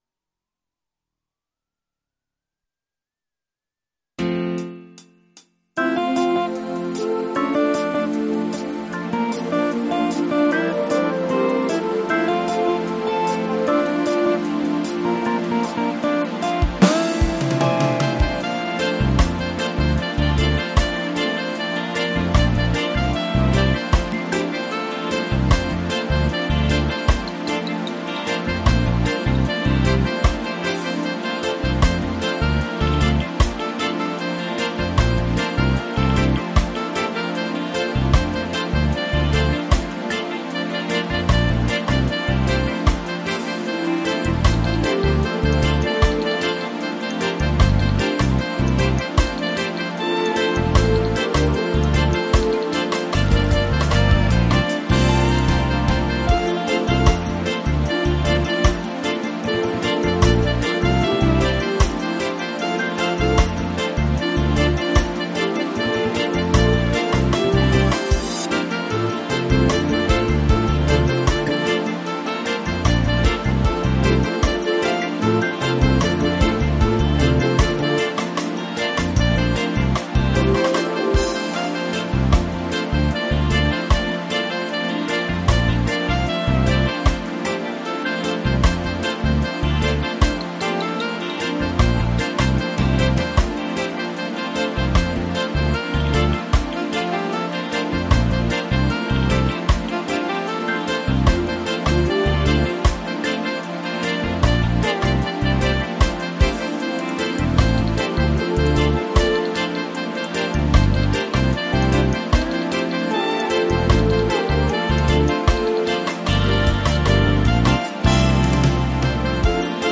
PRO MIDI Karaoke INSTRUMENTAL VERSION